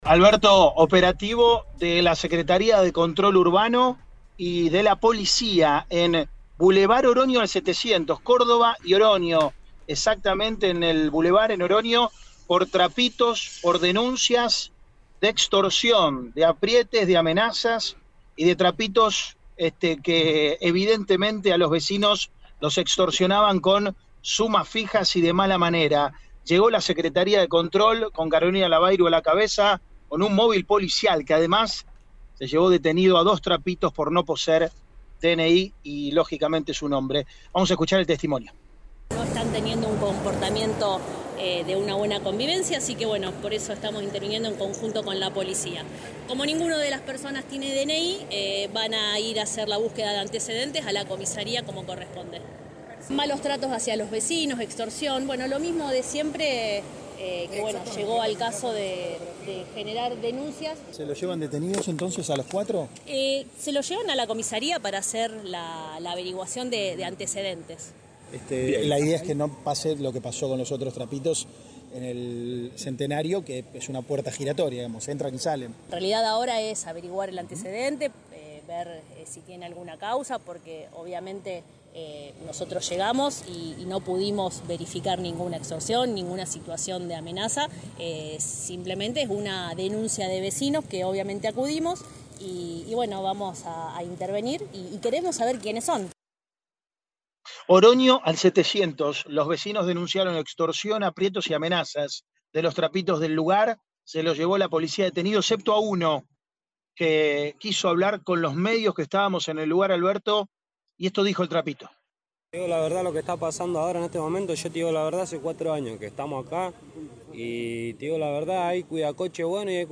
Carolina Labayru, titular de la Secretaría de Control, dio detalles del operativo que llevaron adelante de forma conjunta con la Policía.